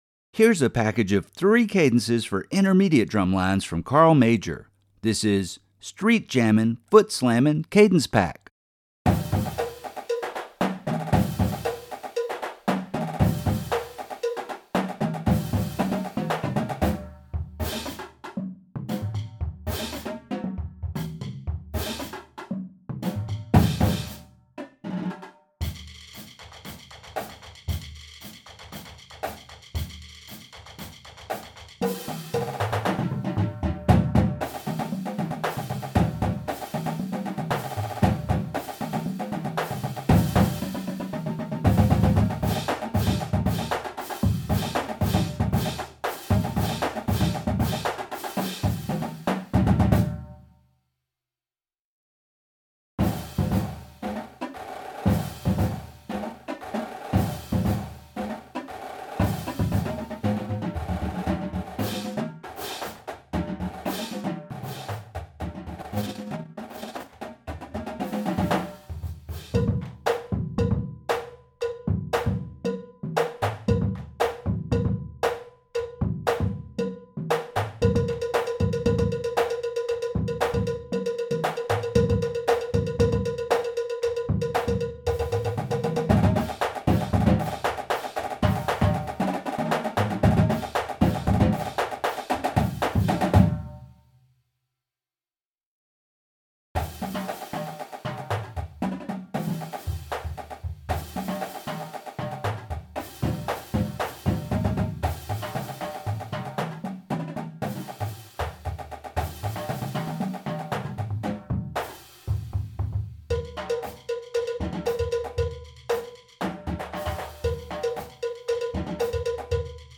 Voicing: Cadence